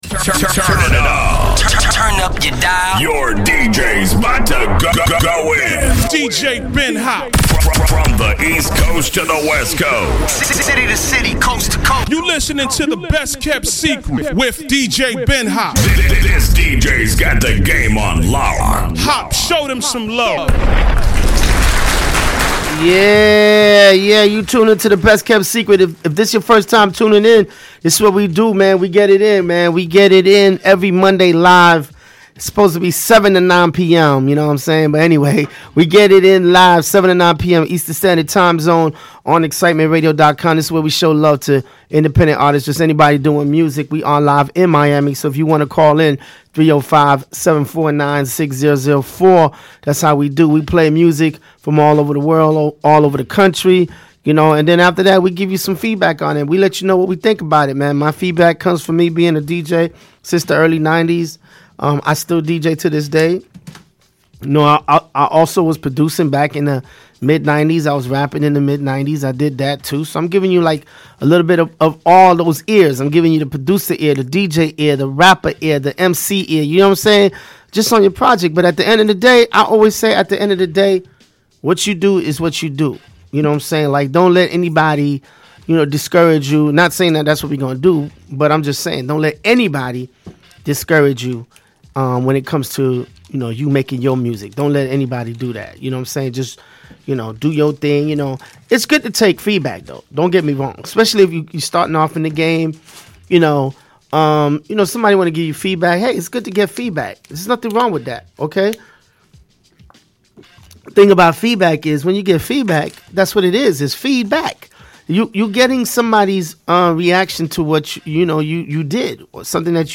We are showcasing Independent Artist from all over the world.